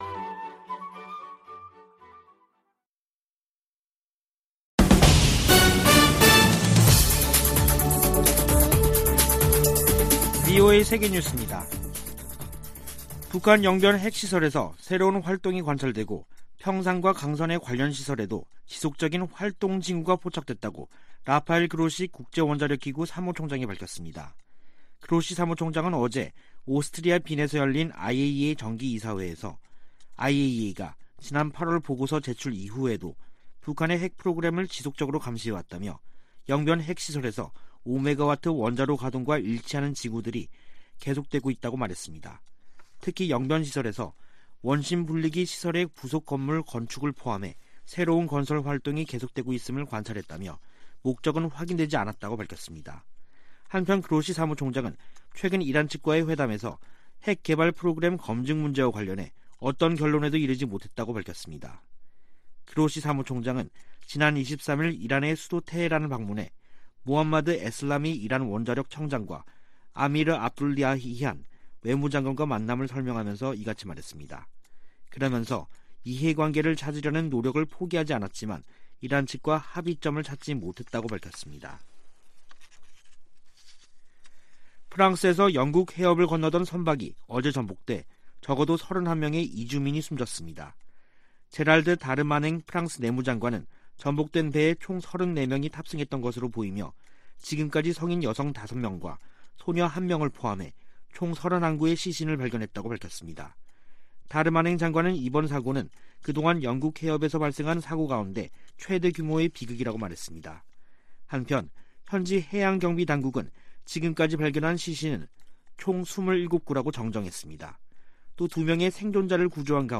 VOA 한국어 간판 뉴스 프로그램 '뉴스 투데이', 2021년 11월 25일 2부 방송입니다. 국제원자력기구(IAEA)는 영변 핵 시설에서 새로운 활동이 관찰되고 평산과 강선의 관련 시설에서도 지속적인 활동 징후가 포착됐다고 밝혔습니다. 북한은 의도적 핵활동 노출로 미국을 압박하고 이를 협상력을 높이는 지렛대로 활용하려는 계산이라고 한국의 전문가가 분석했습니다. 미국과 한국의 한국전쟁 종전선언 논의가 문안을 마무리하는 단계에 있다고, 미국 정치 전문 매체가 보도했습니다.